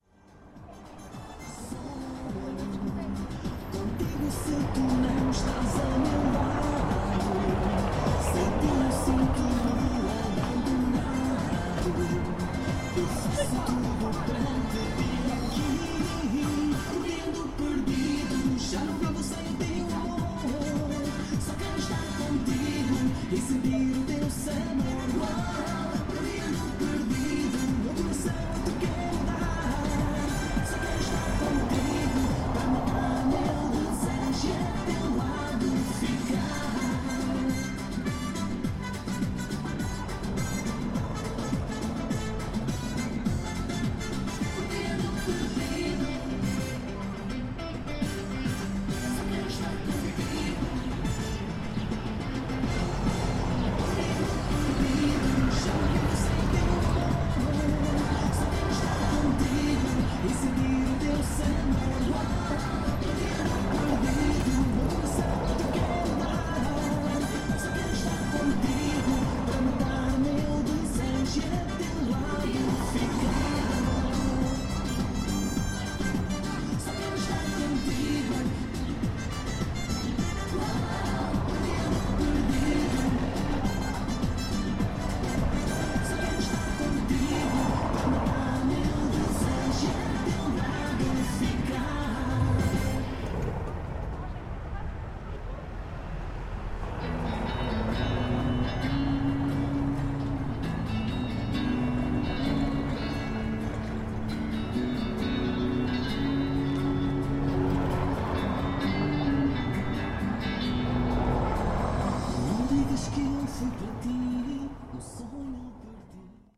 Gravação junto ao Teatro Viriato e do som de música pimba que da discoteca Ferrão. Gravado com Zoom H4.
Tipo de Prática: Paisagem Sonora Rural
Viseu-Av.-Emidio-Navarro-À-porta-da-Discoteca-Ferrão.mp3